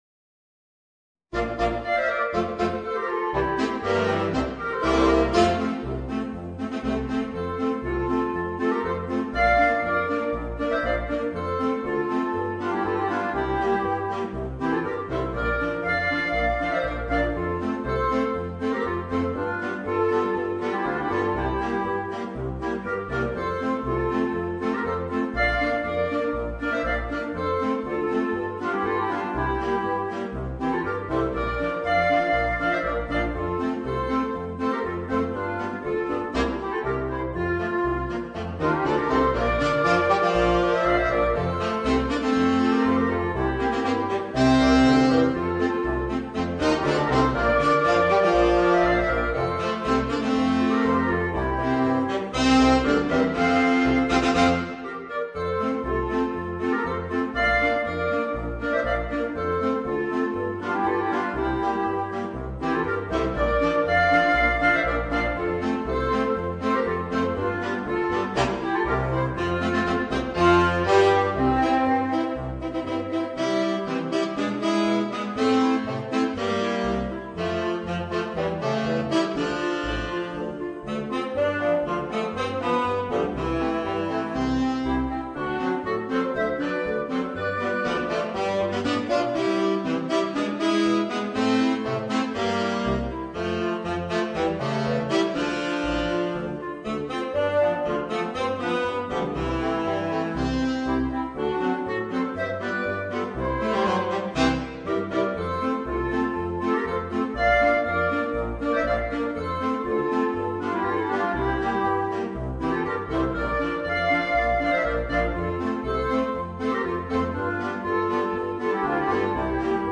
Woodwind Quintet
Ensemblemusik für 5 Holzbläser